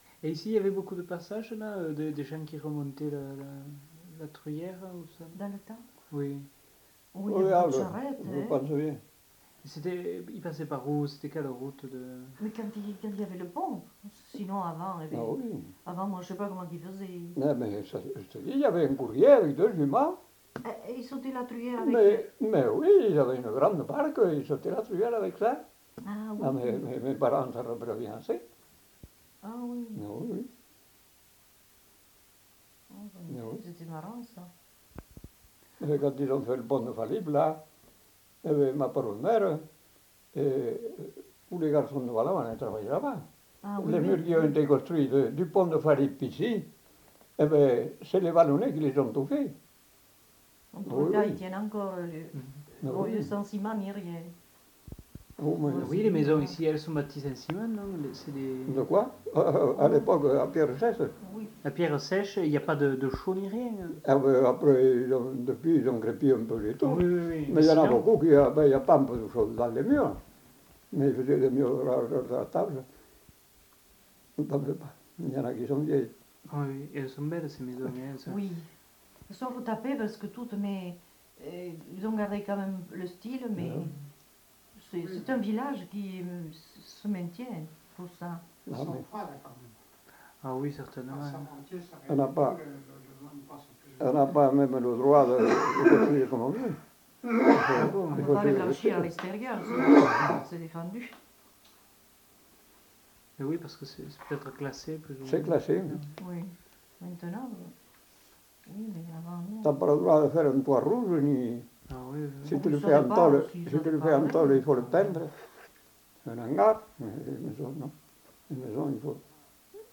Lieu : Lacroix-Barrez
Genre : témoignage thématique